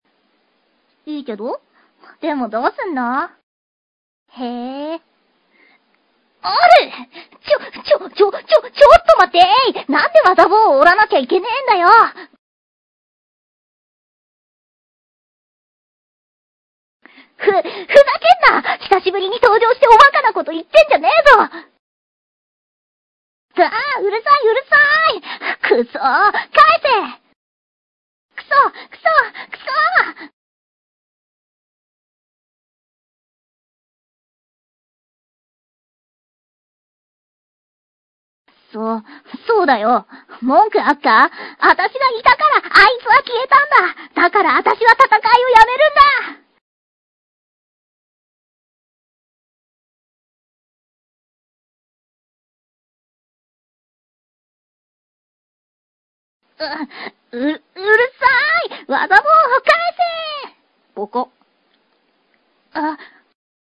ぼーを取り合うシーンをむむ君の台詞を抜いて穴埋め風にしてみました。
後悔は言い出すとキリがないですが、出だしの演技が酷い、最後の切り方が先のファイルと同趣向で工夫がない、ボコって口で言うのは何ごとか・・・・　とか。